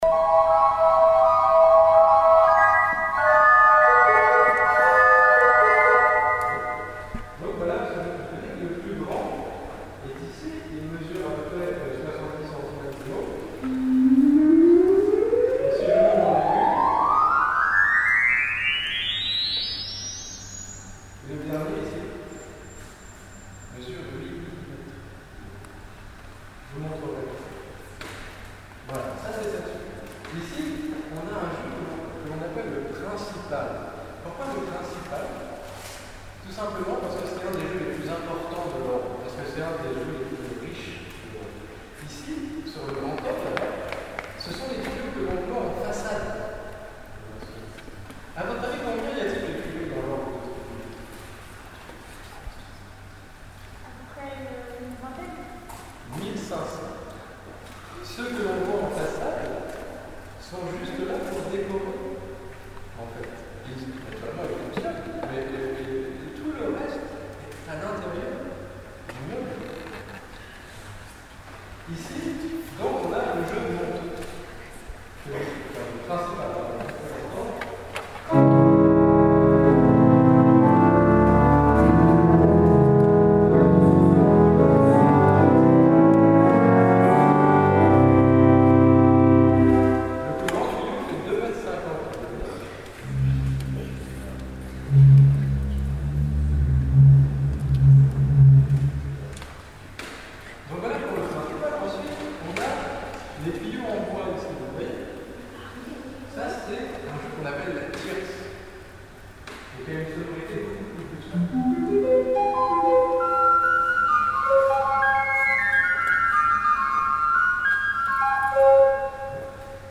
Un orgue
Quelques sons de l’orgue (avec les explications de l’organiste) :
sons-de-lorgue.mp3